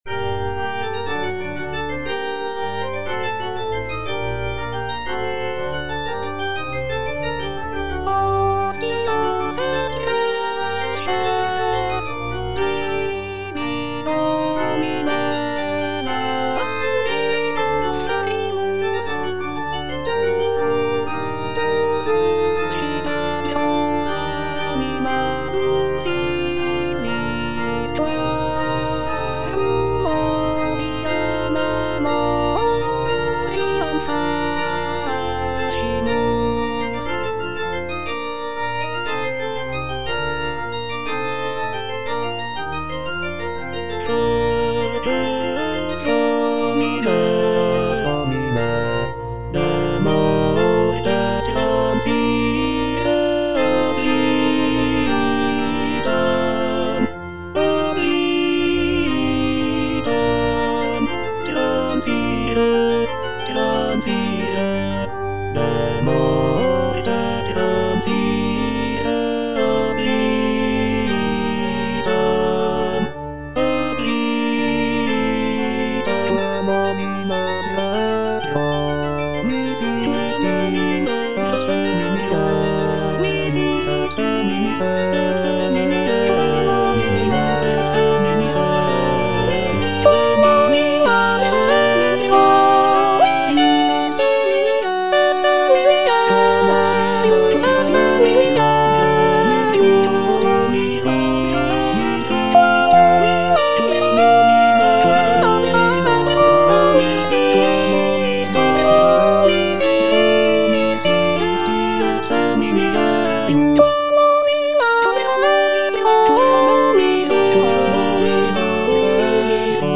Soprano (Emphasised voice and other voices) Ads stop